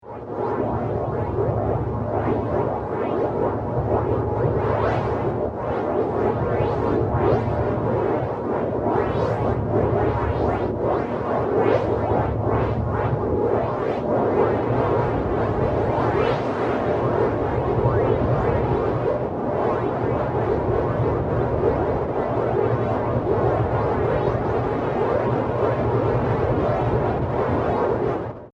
The “sound stroke” signatures were re-synthesized with a computational feedback synthesis system, using a sine tone as input (Audio 3).
Audio 3 (0:28). Fractal branching and sound stroke chorusing re-synthesis from a sine tone.
A spectrogram (Fig. 7) shows the sound of fractal branching re-synthesis generating these types of iteratively rising pitch signatures.